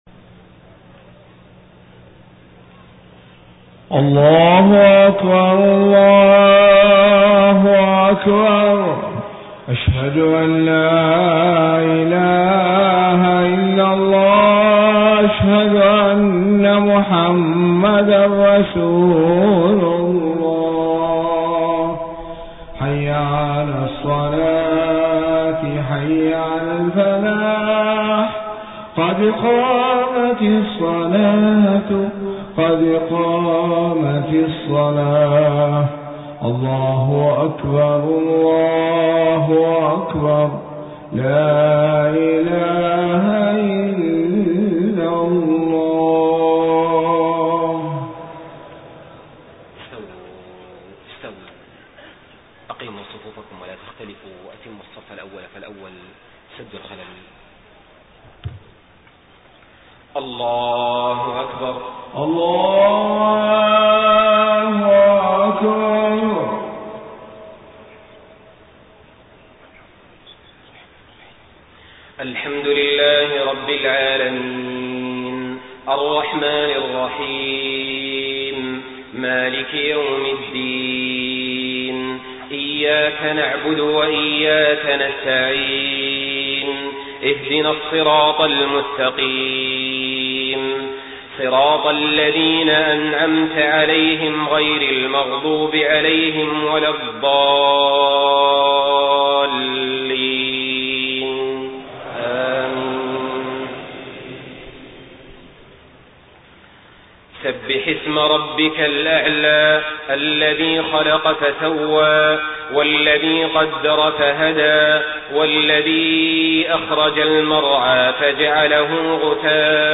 صلاة الجمعة 5 ربيع الأول 1431هـ سورتي الأعلى و الغاشية > 1431 🕋 > الفروض - تلاوات الحرمين